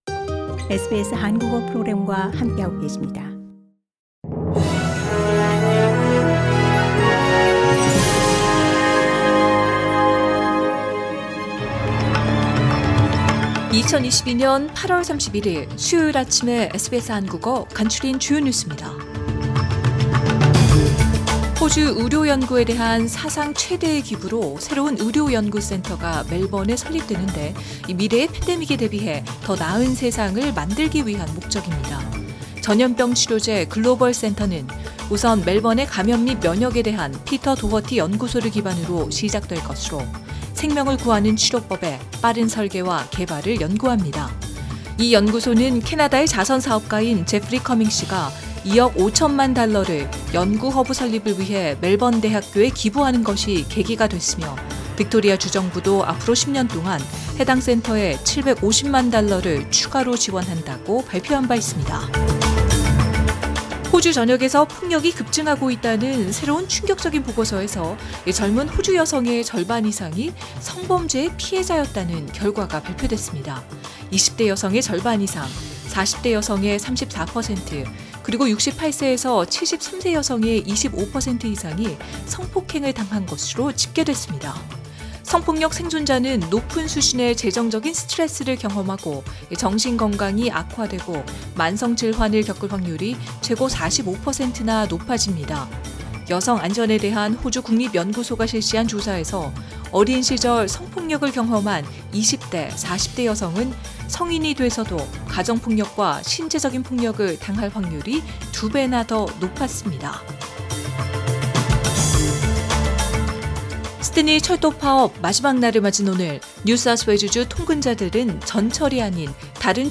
2022년 8월 31일 수요일 아침 SBS 한국어 간추린 주요 뉴스입니다.